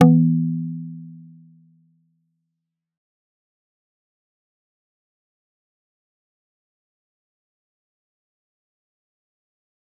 G_Kalimba-E3-f.wav